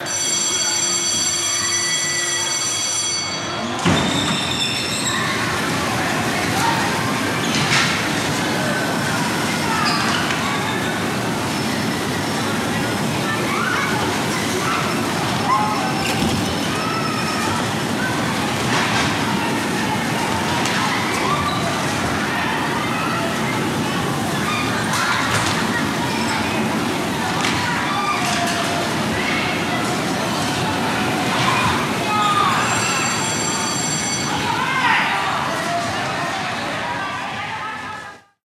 Parque de atracciones: coches de choque